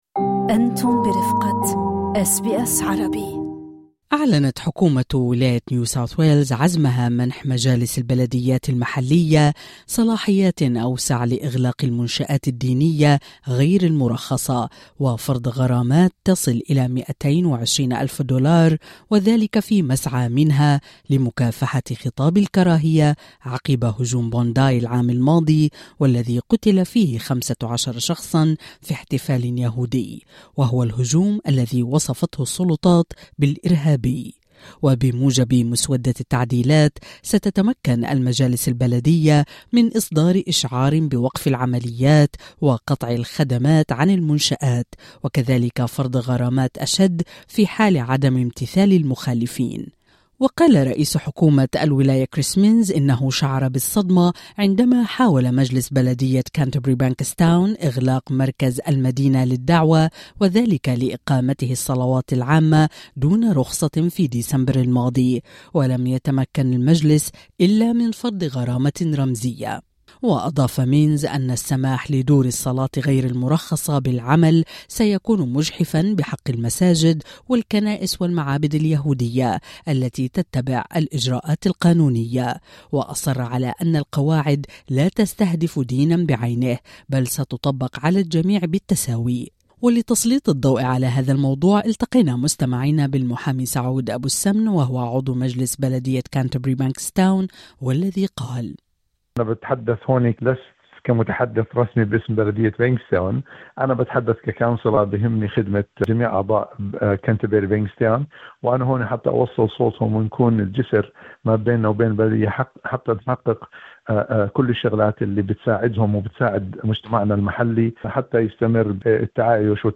اقرأ المزيد وصاية دولية واجهاض لحلم الدولة الفلسطينية: باحث ينتقد مجلس السلام في غزة الذي سيقوده ترامب لتسليط الضوء على هذا الموضوع التقينا مستمعينا بالمحامي سعود ابو السمن عضو مجلس بلدية كانتربري بانكستاون والذي قال اعتقد ان حكومة الولاية يجب ان تتولى مسؤولية اغلاق دور العبادة غير المرخصة وليس البلديات فهي تملك الاليات والموارد كالشرطة ويمكنهم القيام بذلك بطريقة تحمي حقوق الجميع هل أعجبكم المقال؟